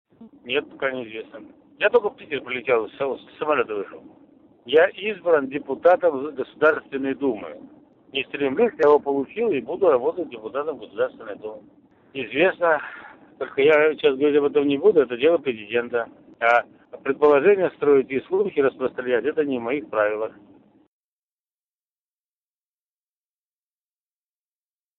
Вячеслав Позгалев после своей отставки будет работать в Госдуме. Об этом Губернатор Вологодской области сообщил корреспонденту ИА "СеверИнформ - Новости Вологодской области".
Вячеслав Позгалев прокомментировал свою отставку